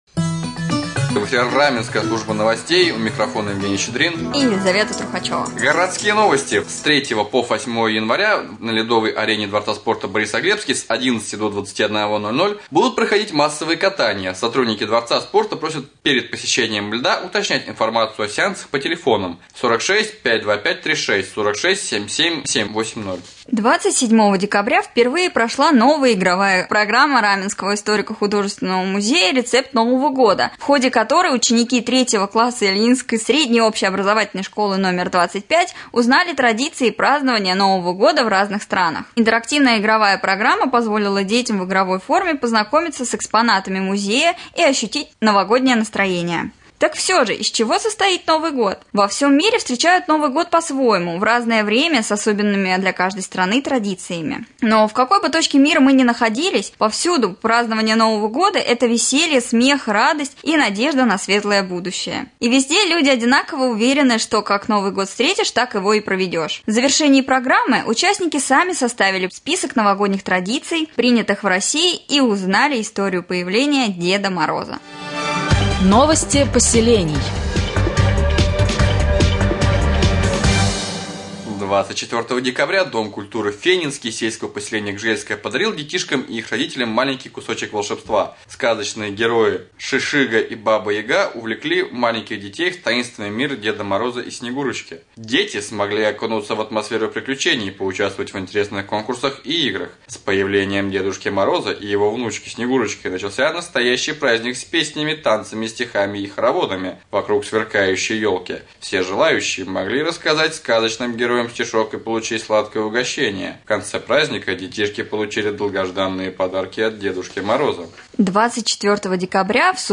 6. Новости